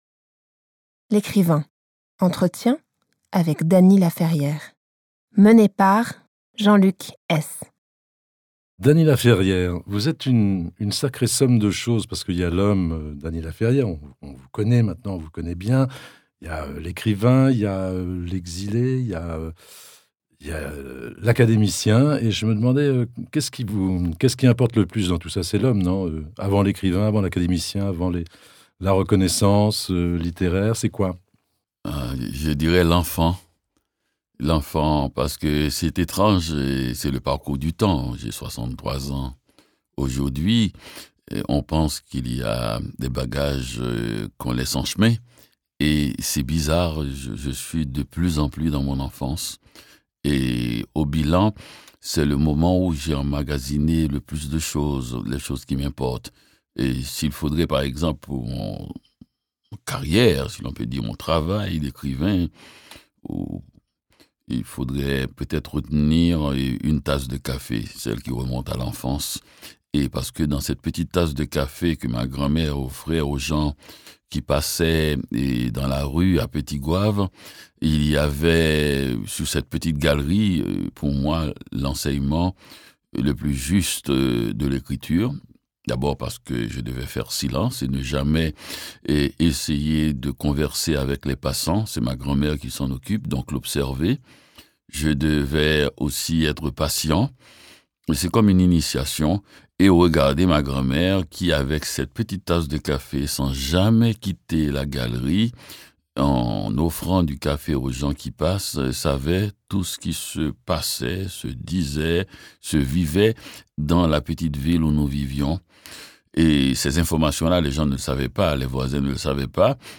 L'Ecrivain - Dany Laferrière - Entretien inédit par Jean-Luc Hees